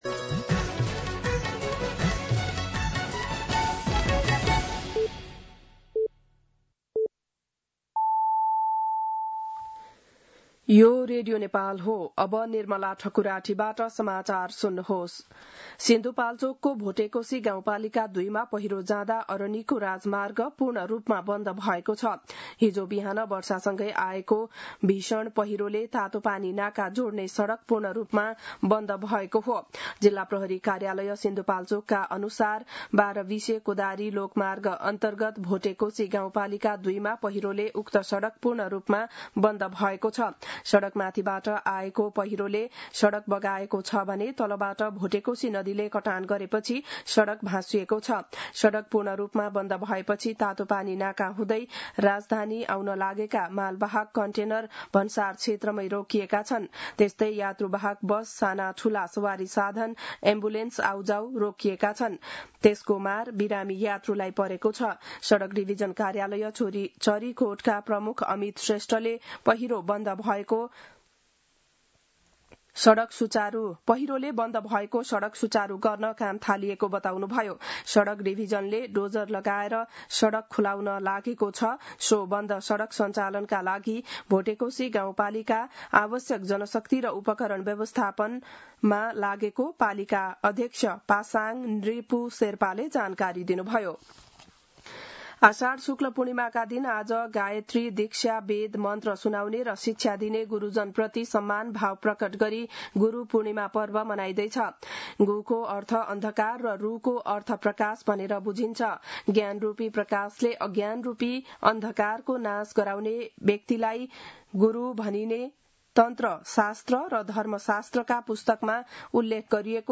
बिहान ११ बजेको नेपाली समाचार : २६ असार , २०८२